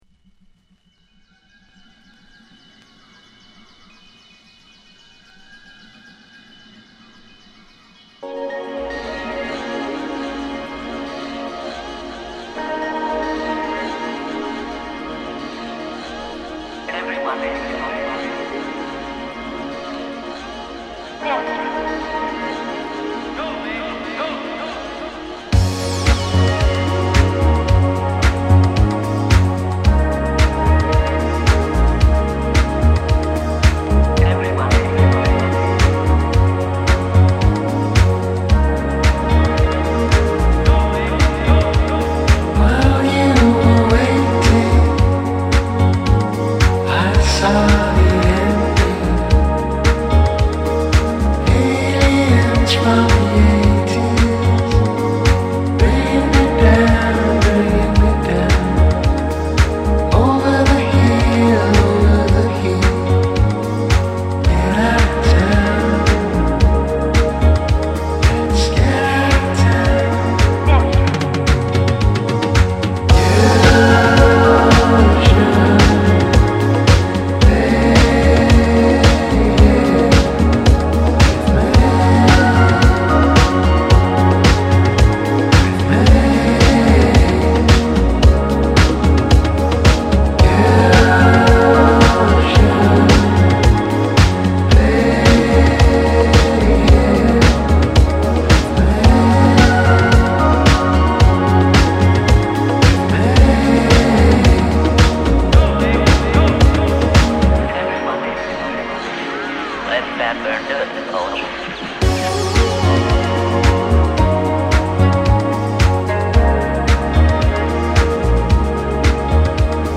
オーガニッっくで心地良い